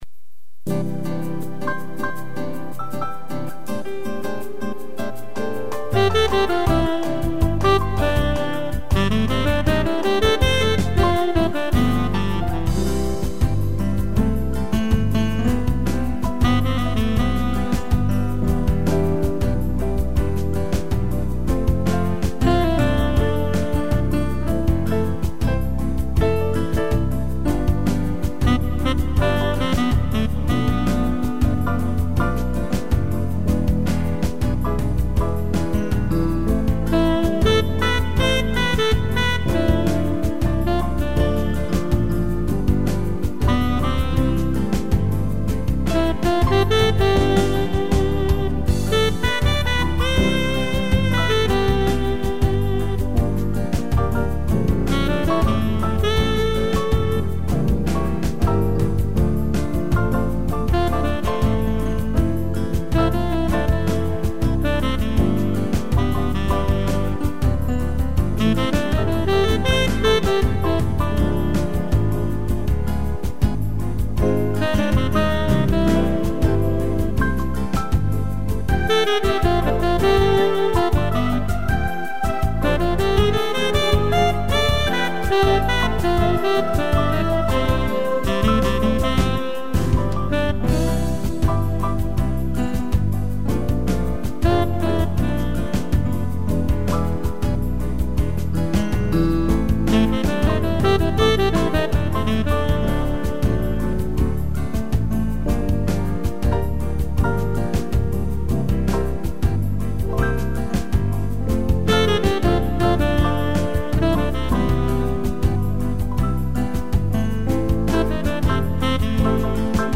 piano e sax
(instrumental)